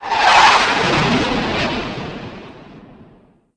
RocketV1-3.mp3